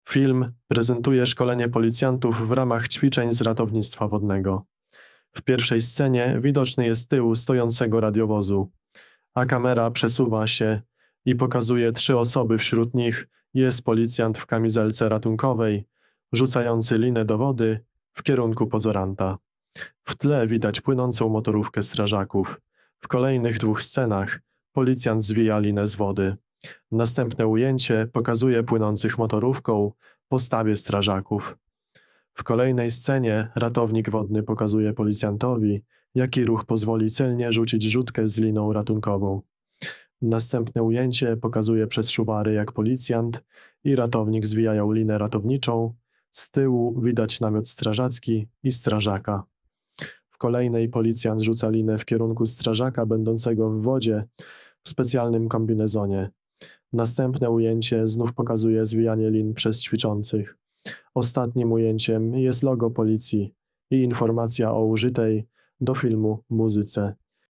Opis nagrania: nagranie audio deskrypcji filmu z ćwiczeń